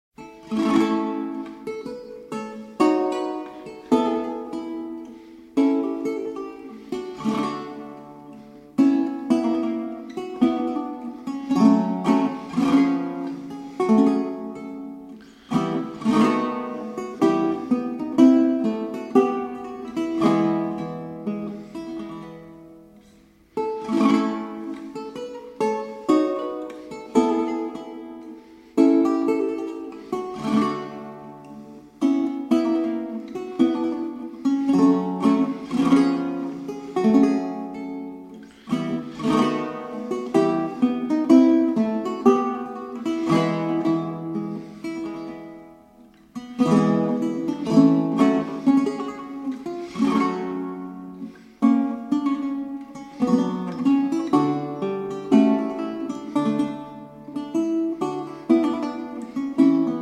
performed on baroque guitar.